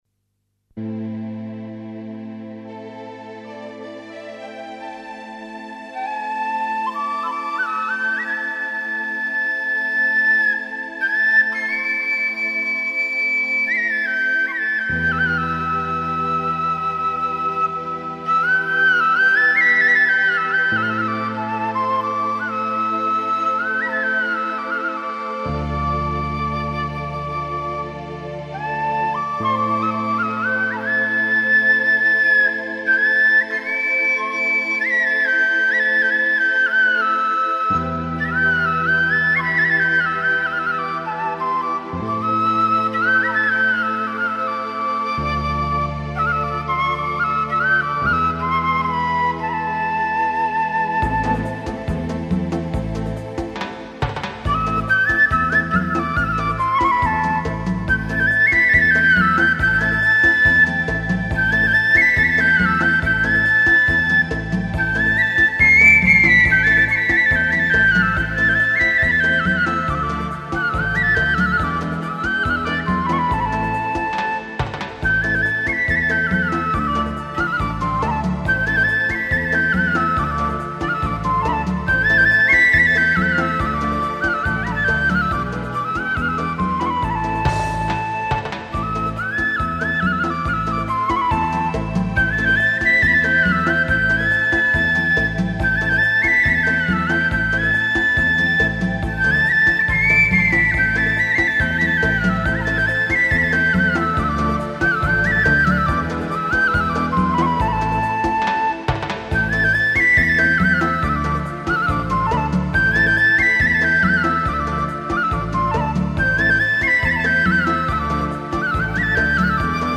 0275-花儿与少年-笛子.mp3